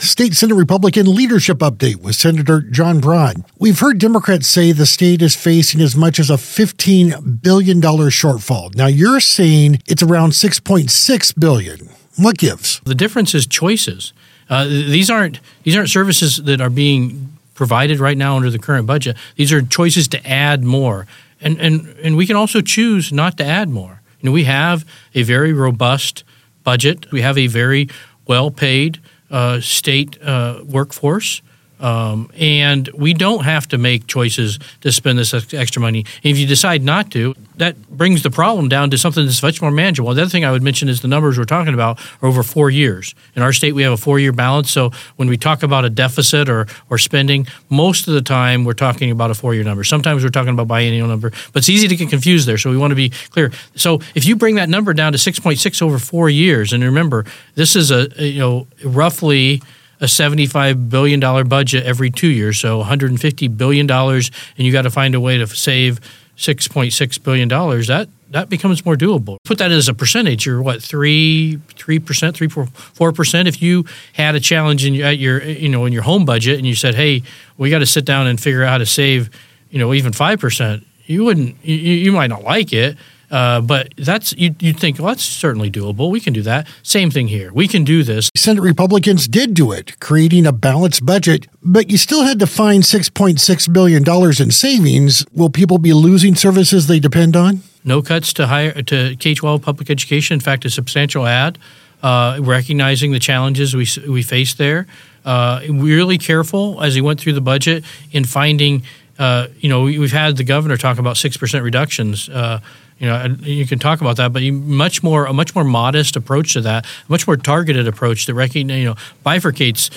AUDIO: State Senate Republican Leadership Update with Senator John Braun…
Budget shortfall and $ave Washington Budget—Senate Republican Leader John Braun explains how the state’s budget shortfall is closer to $6.6 billion—not $15 billion—because of spending choices, not existing services.